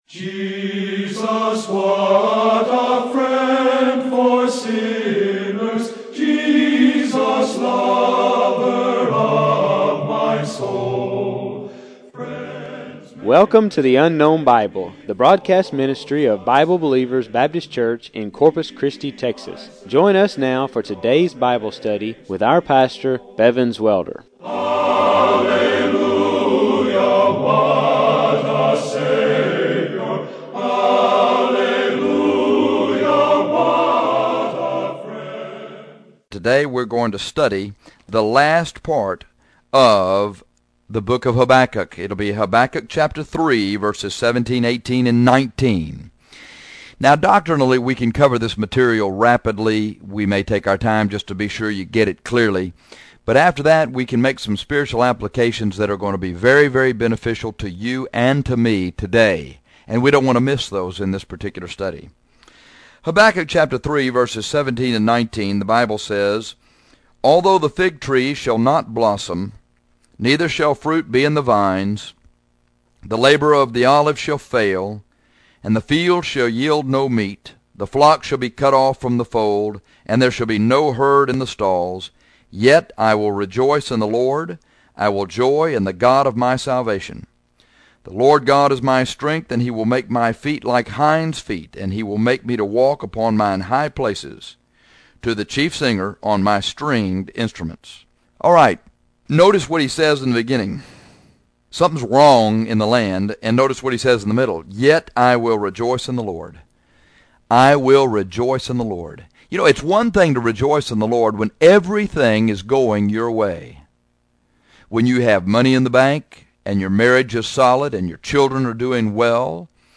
This rejoicing is done in spite of the fact that all the crops and animals have failed. Listen to the audio of this sermon…